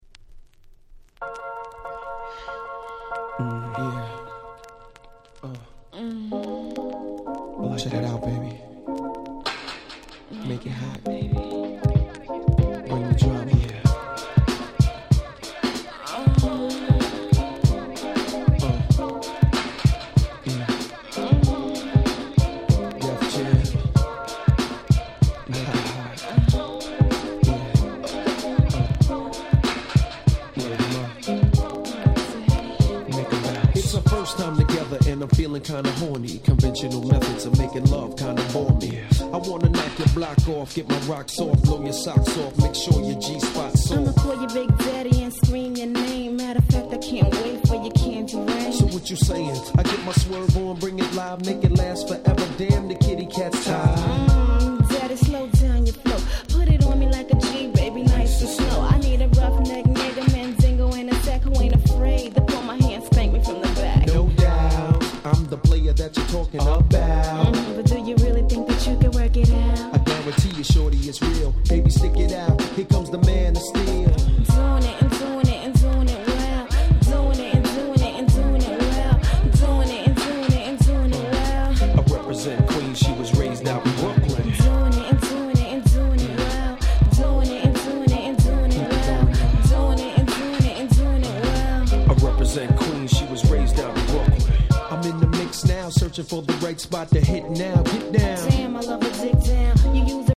95' Super Hit Hip Hop LP !!